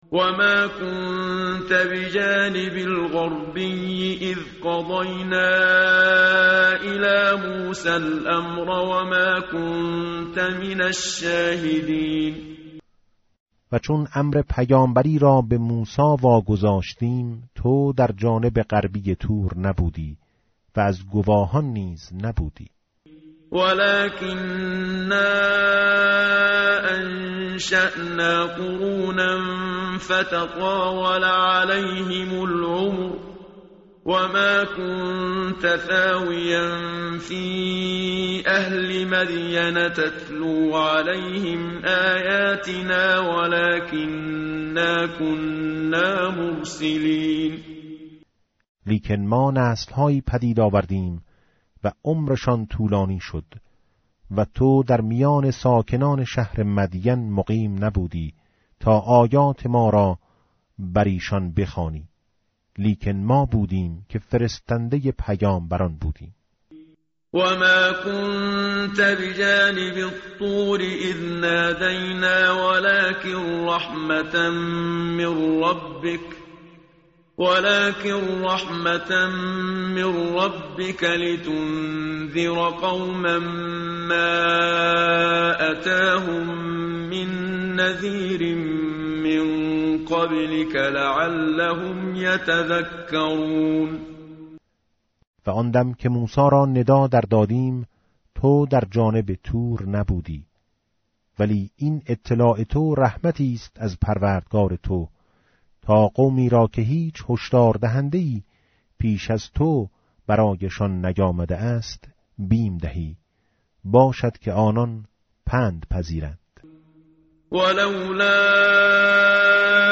tartil_menshavi va tarjome_Page_391.mp3